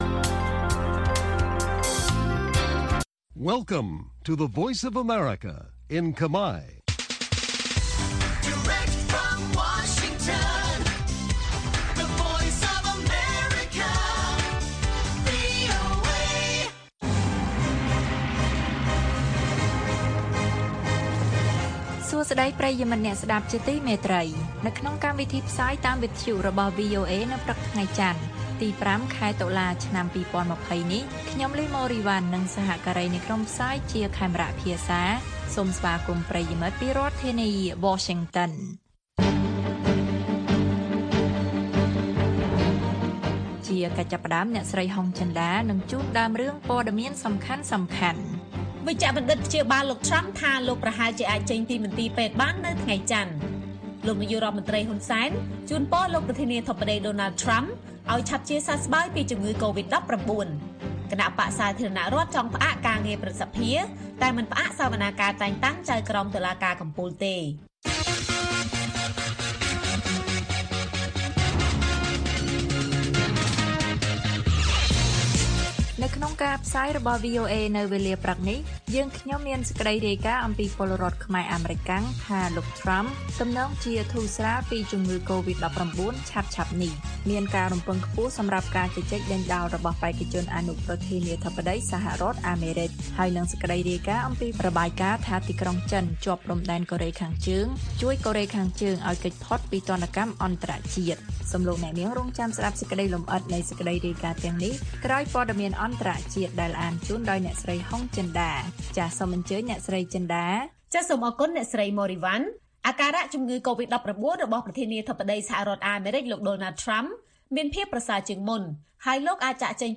ព័ត៌មានពេលព្រឹក៖ ៥ តុលា ២០២០